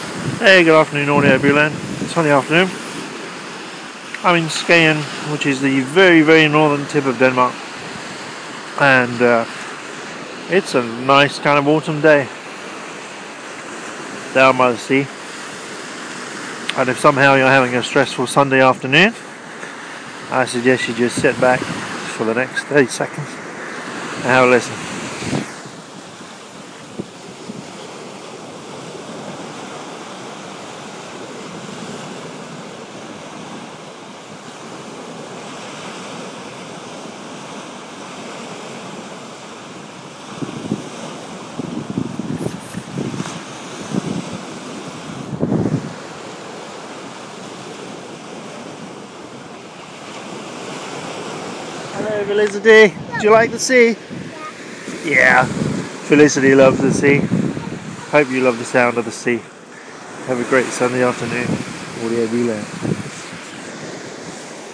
The sound of the sea